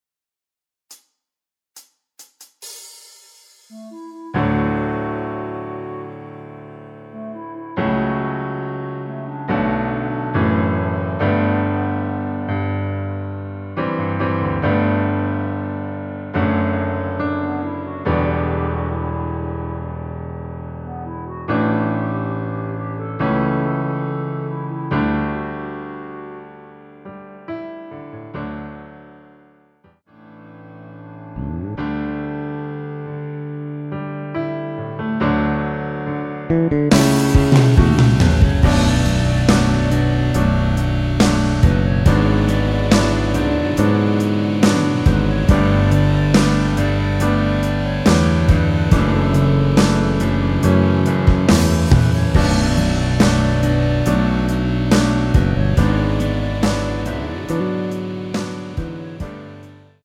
원키에서(-2)내린 멜로디 포함된 MR입니다.
전주없이 노래가 시작되는곡이라 카운트 만들어 놓았습니다.
앞부분30초, 뒷부분30초씩 편집해서 올려 드리고 있습니다.
중간에 음이 끈어지고 다시 나오는 이유는